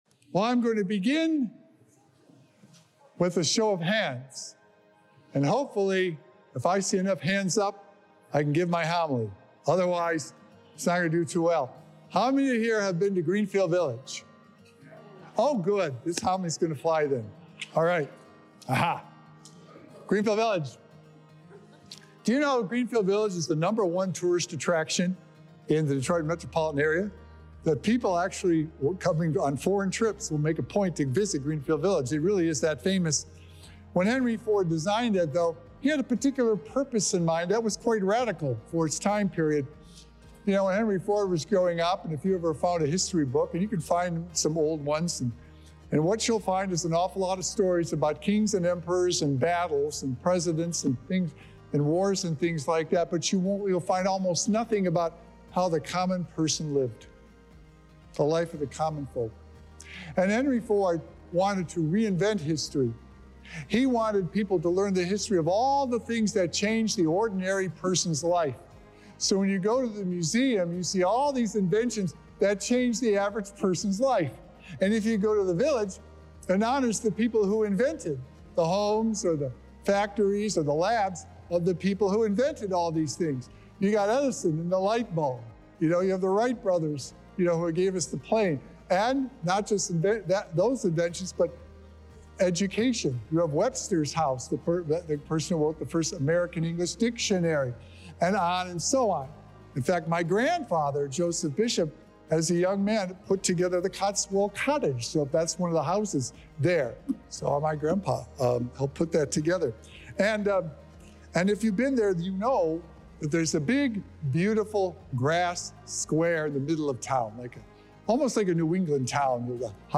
Sacred Echoes - Weekly Homilies Revealed
Join us for a heartfelt homily reflecting on the significance of Christ as our Good Shepherd, drawing inspiration from the unique setting of Greenfield Village in Dearborn, Michigan.